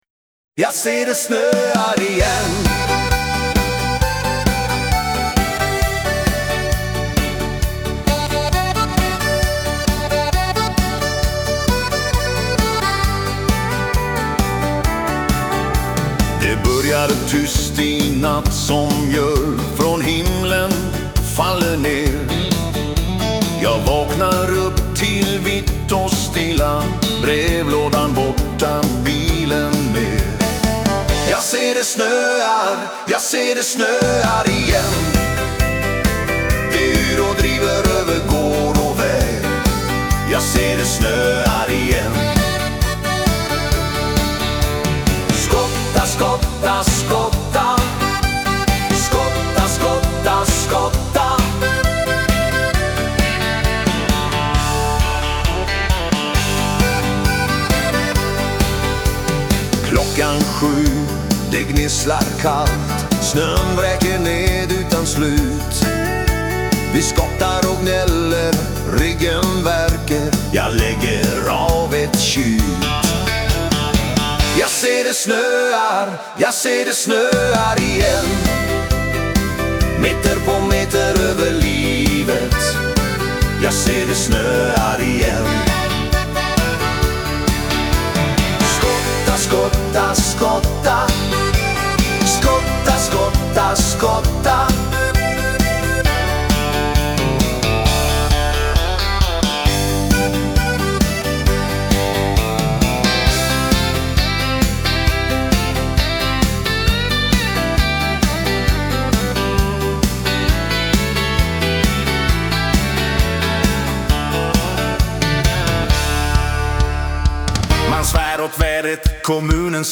humor och ett stadigt dansbandssväng.